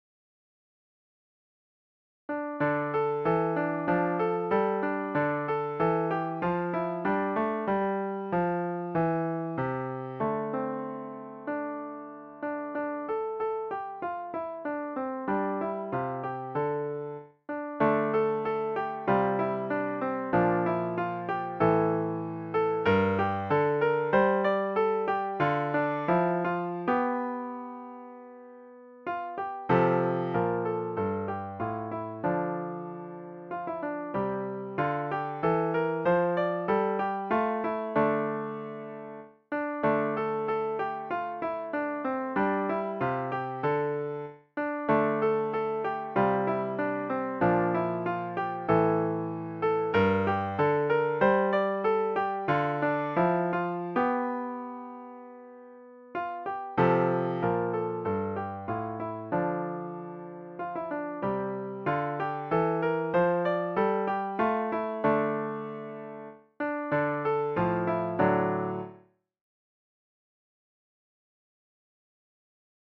Christmas Piano Book – intermediate to late intermediate